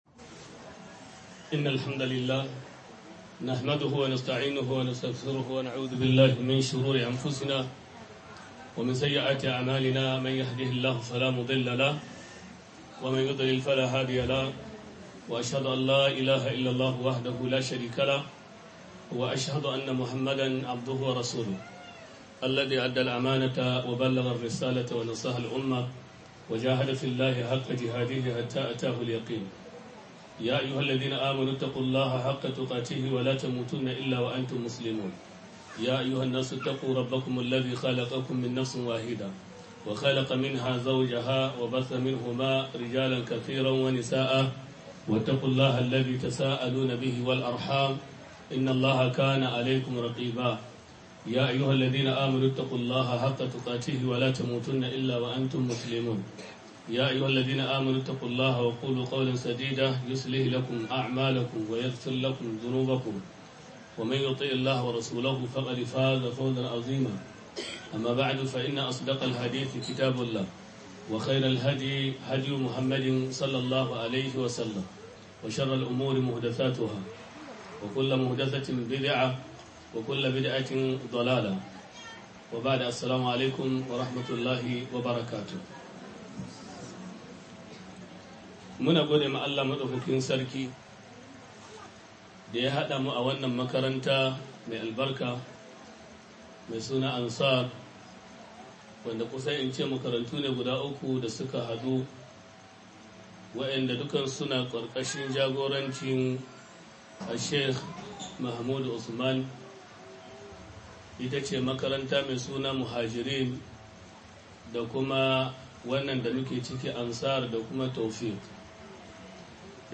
محاضرة دور المرأة برعاية الأسرة~1